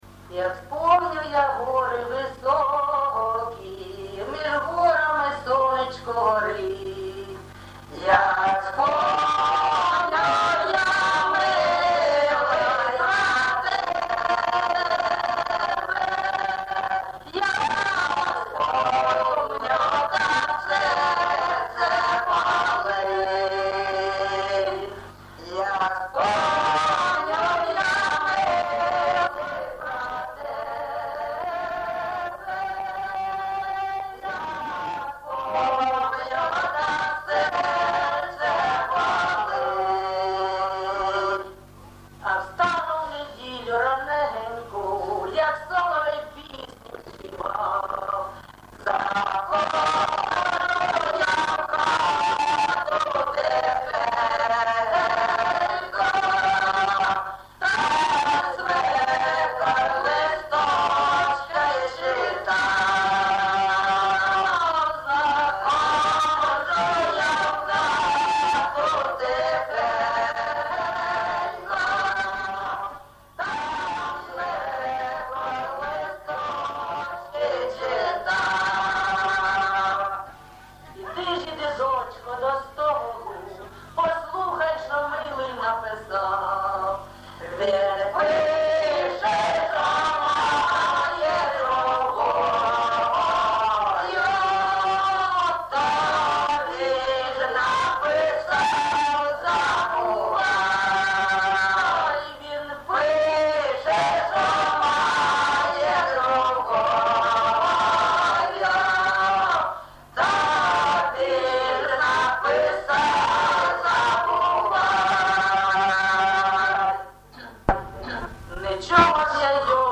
ЖанрПісні з особистого та родинного життя, Сучасні пісні та новотвори
Місце записум. Єнакієве, Горлівський район, Донецька обл., Україна, Слобожанщина